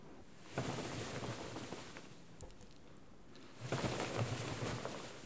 Les sons ont été découpés en morceaux exploitables. 2017-04-10 17:58:57 +02:00 908 KiB Raw Permalink History
animal-secoué_01.wav